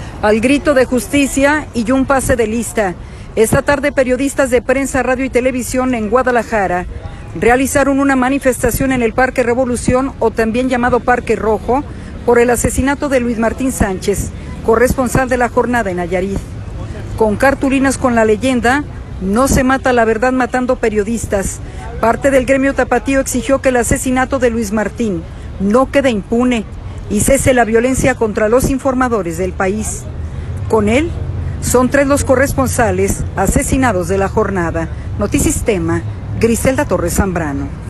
Al grito de justicia y un pase de lista, esta tarde periodistas de prensa, radio y televisión en Guadalajara, realizaron una manifestación en el Parque Revolución o también llamado Parque Rojo